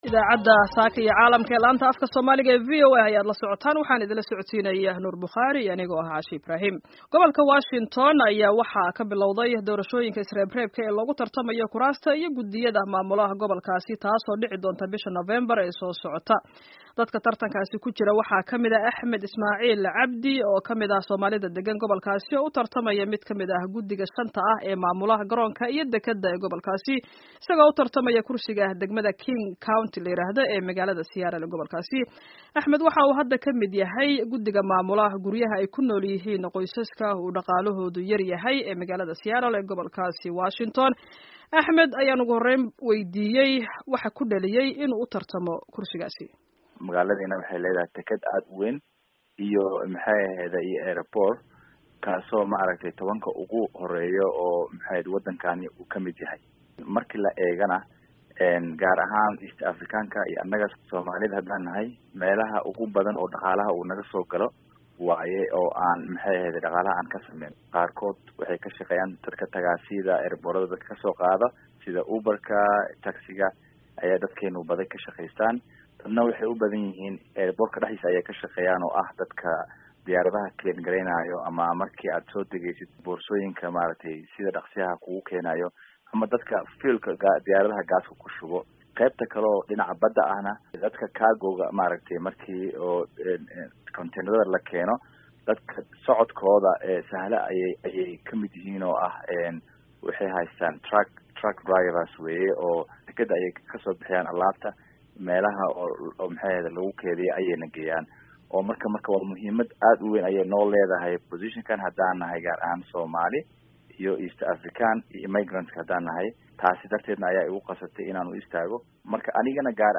Wareysiga